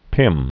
(pĭm), Barbara 1913-1980.